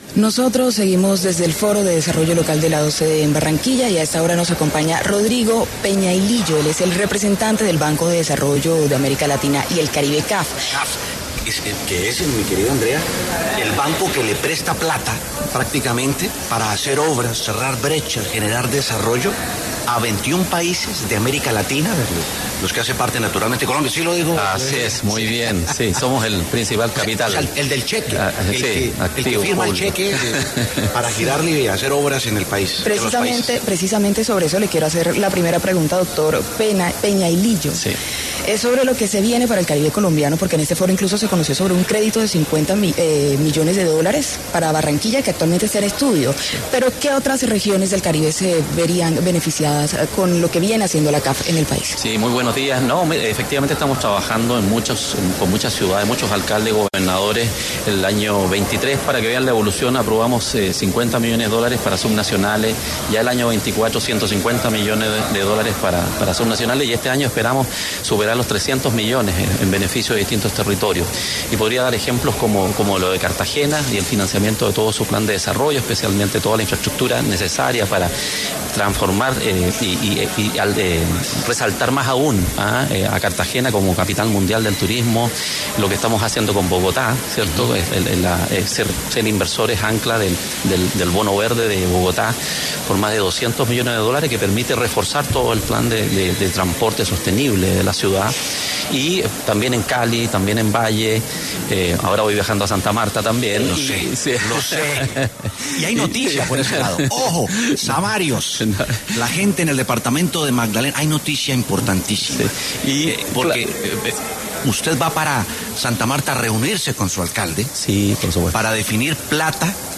Rodrigo Peñailillo Briceño, representante del Banco de Desarrollo de América Latina y el Caribe, CAF, pasó por los micrófonos de La W en el marco del Foro de Desarrollo Local de la OCDE 2025 que se lleva a cabo en Barranquilla.